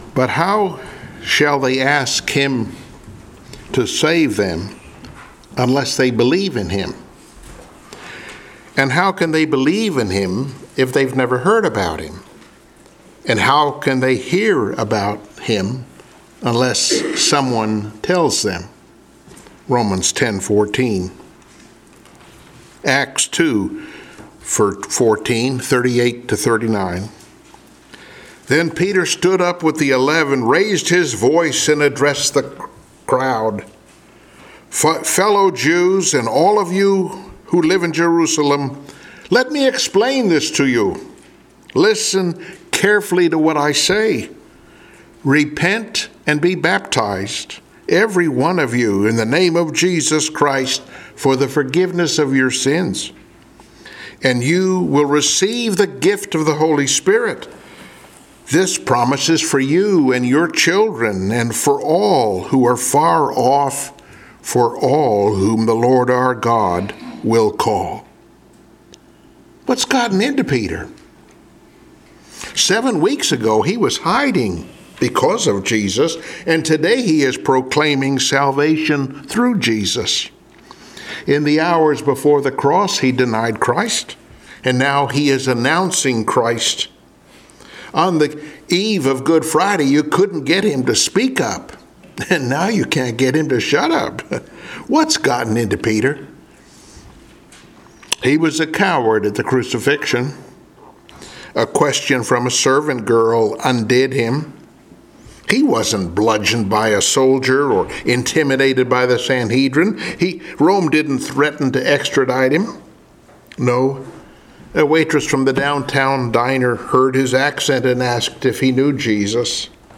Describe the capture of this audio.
Passage: Acts 2:14, 38-39 Service Type: Sunday Morning Worship